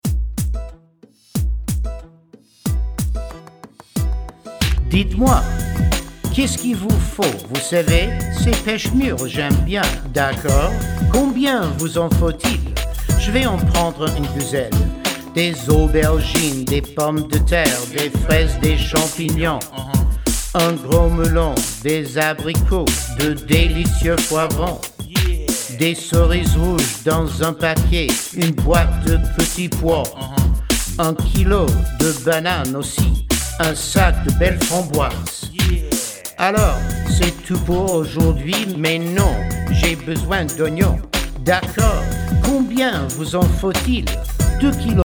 French Language Raps